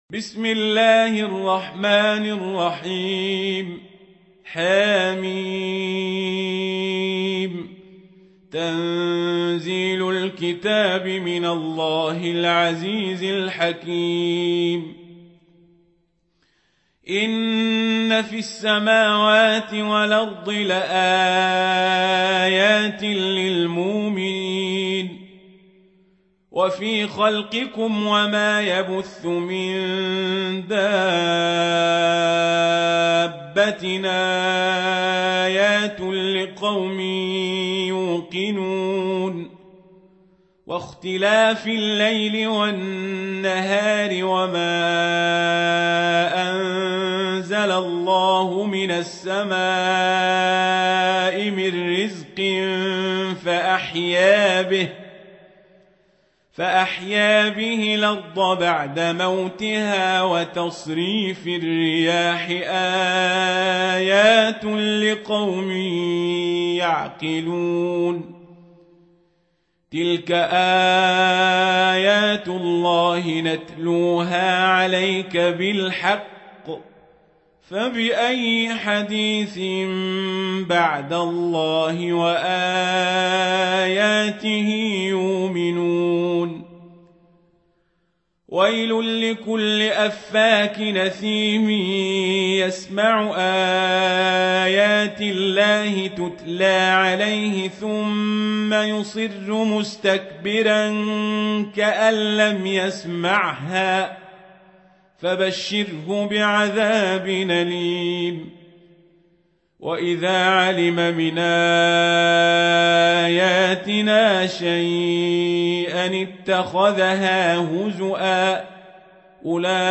سورة الجاثية | القارئ عمر القزابري